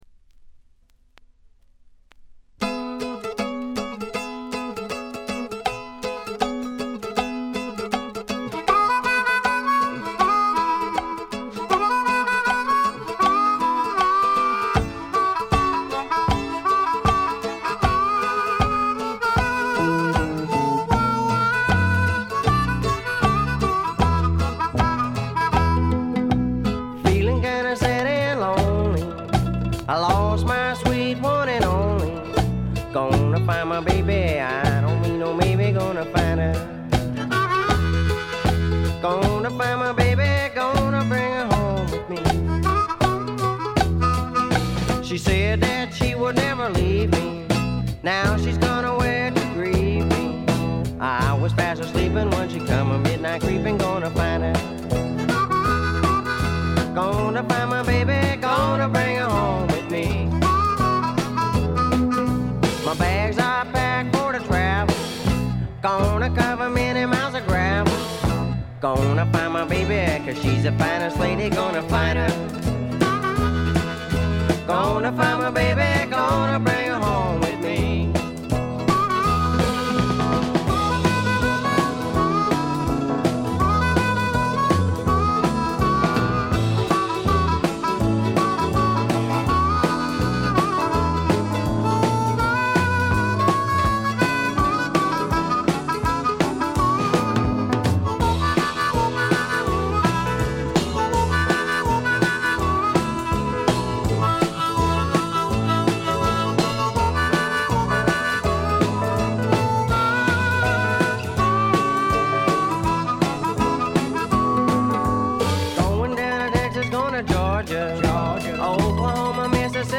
部分試聴ですが、チリプチ少々。
試聴曲は現品からの取り込み音源です。